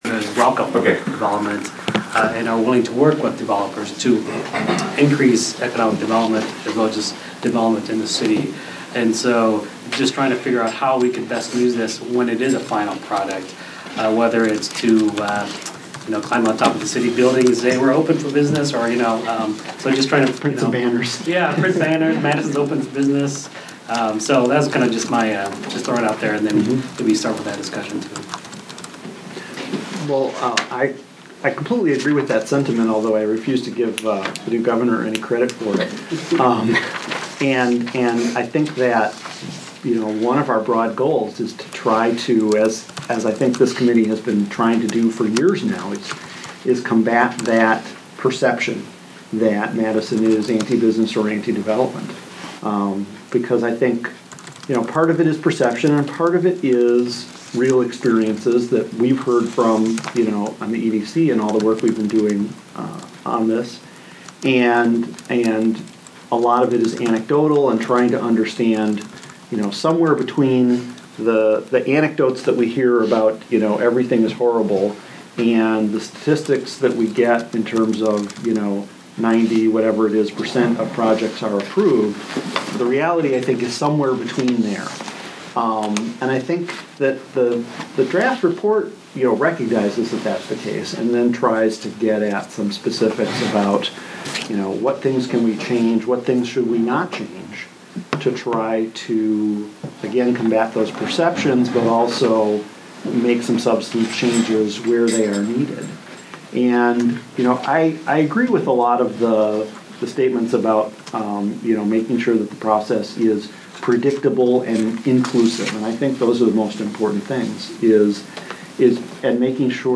I didn’t immediately start recording, and there’s an interruption in the middle (it turns out that if use my iPhone to record the meeting, my recording software stops if someone calls me. Oops.)